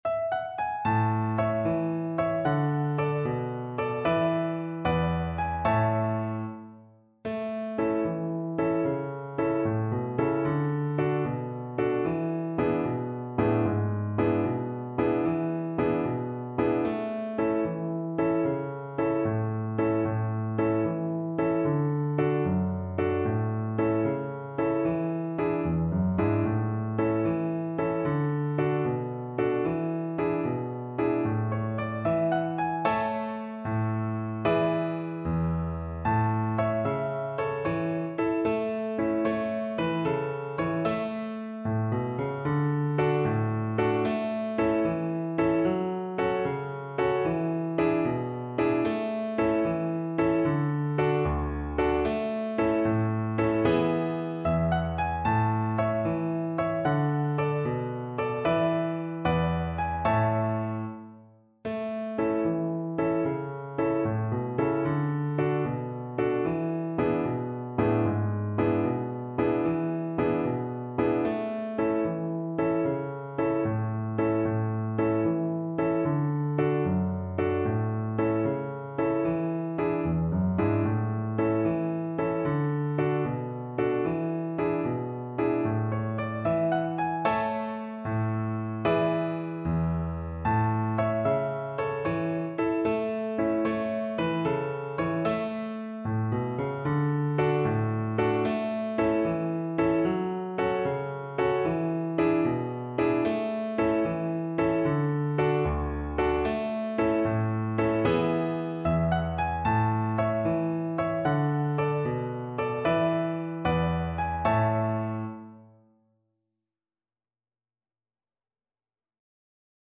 A major (Sounding Pitch) (View more A major Music for Violin )
6/8 (View more 6/8 Music)
Traditional (View more Traditional Violin Music)